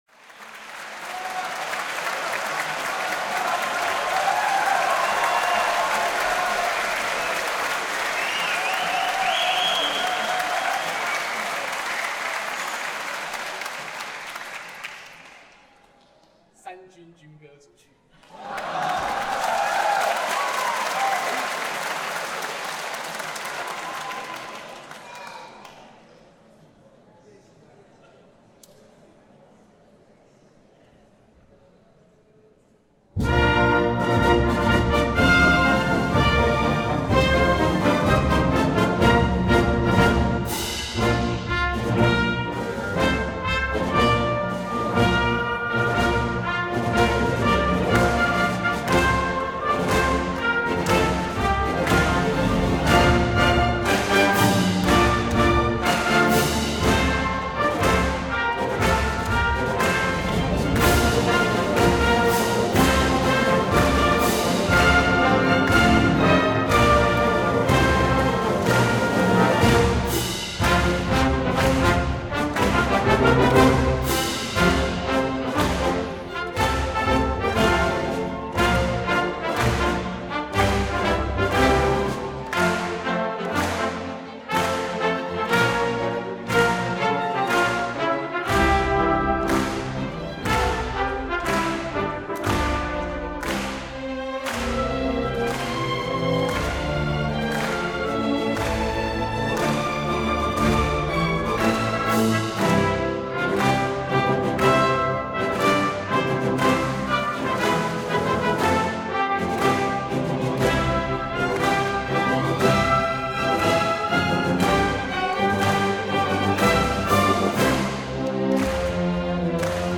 中華民國三軍軍歌.mp3.ogg